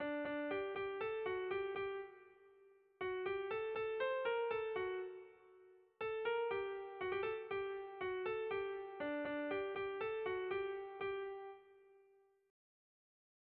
Sehaskakoa
A-B-A